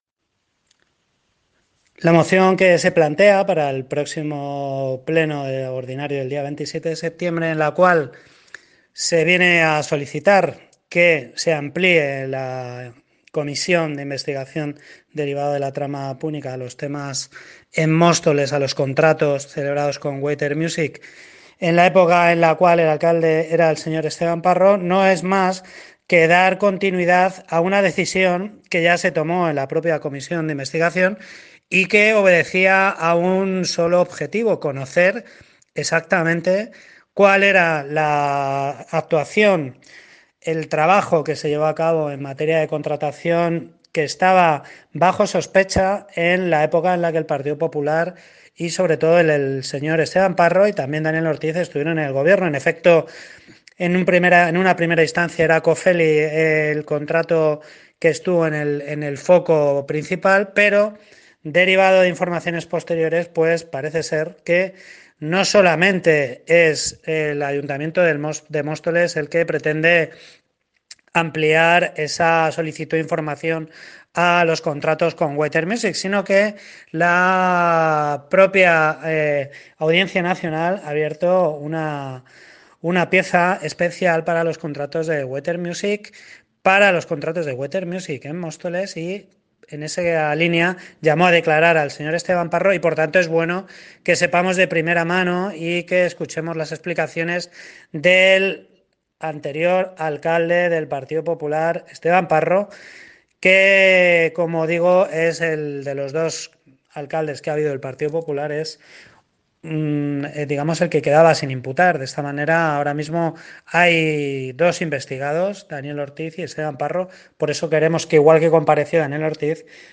Audio -Eduardo Gutiérrez (Concejal Grupo Izquierda Unida - Los Verdes Sobre) Pleno Púnica